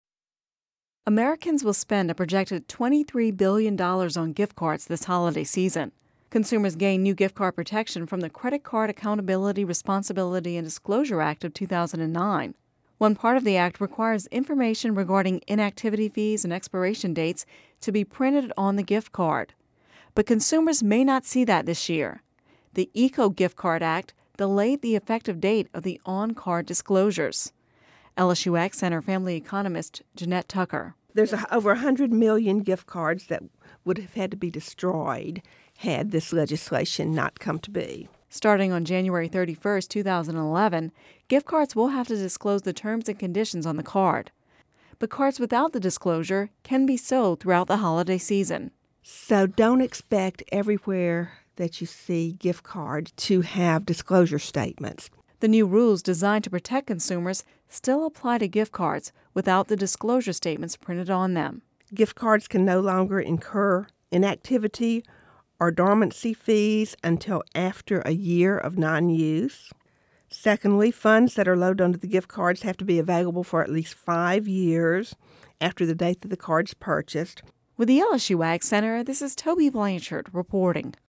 (Radio News 12/13/10) Americans will spend a projected $23 billion on gift cards this holiday season.